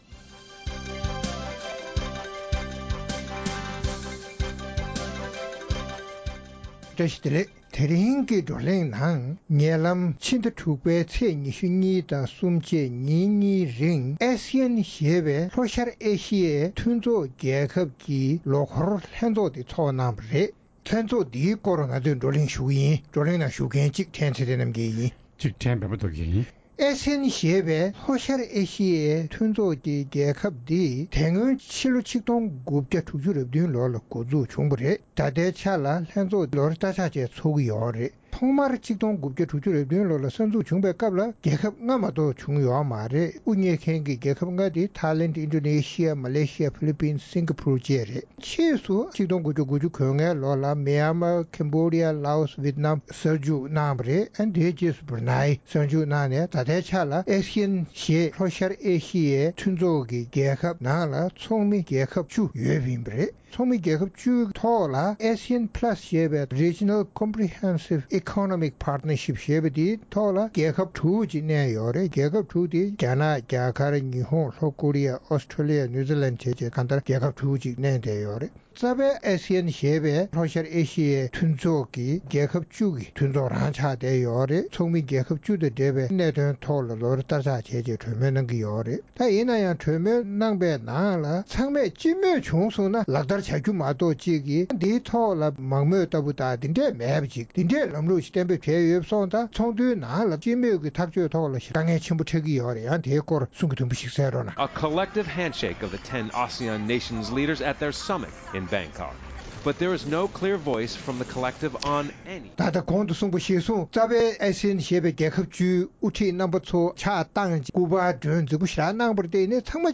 ASEAN ཞེས་པའི་ལྷོ་ཤར་ཨེ་ཤ་ཡའི་མཐུན་ཚོགས་རྒྱལ་ཁབ་བཅུ་ཡི་ལོ་འཁོར་ལྷན་ཚོགས་ཐེངས་༣༤འདི་བཞིན་སྦེང་ཁོག་ཏུ་ཚོགས་ཏེ་གཙོ་བོ་དཔལ་འབྱོར་འབྲེལ་ལམ་སྔར་ལས་ལྷག་པ་བྱུང་ཐབས་སུ་འབད་བརྩོན་གནང་རྒྱུ་སོགས་ཀྱི་སྐོར་ལ་རྩོམ་སྒྲིག་འགན་འཛིན་རྣམ་པས་བགྲོ་གླེང་གནང་བ་ཞིག་གསན་རོགས་གནང་།